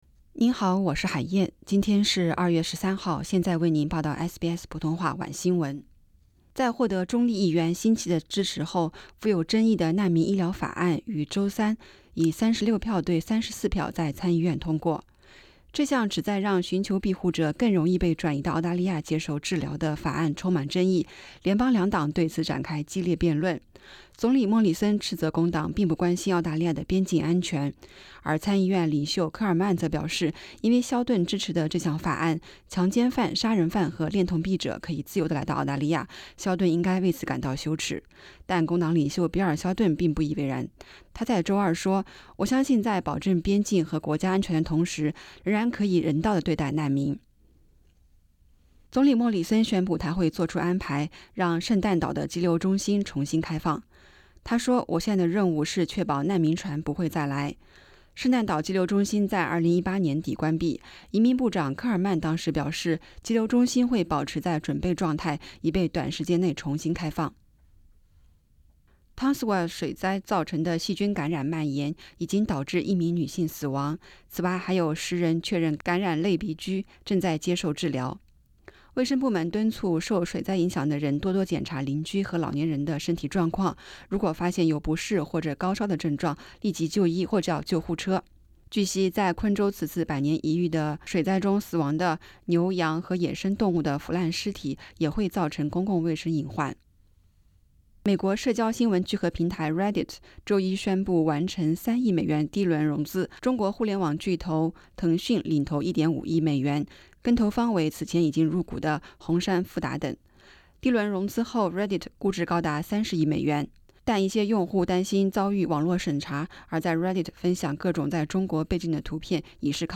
SBS晚新闻 （2月13日）